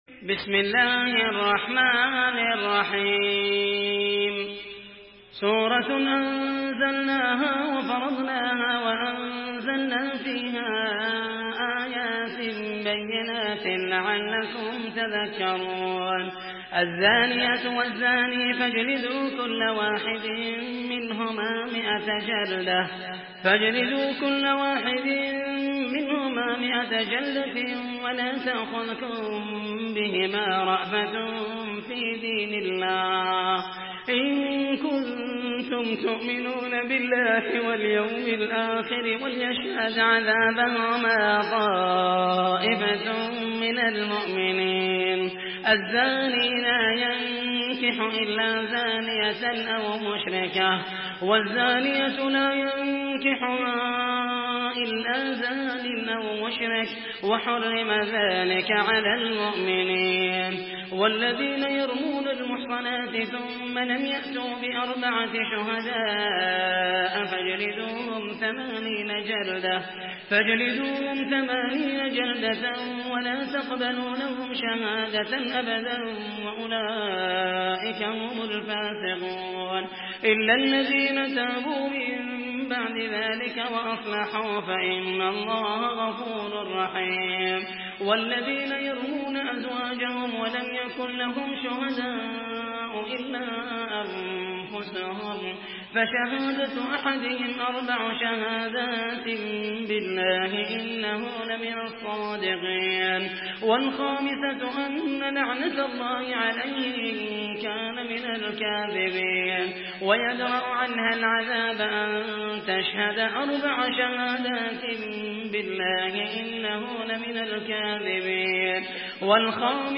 Surah আন-নূর MP3 by Muhammed al Mohaisany in Hafs An Asim narration.
Murattal Hafs An Asim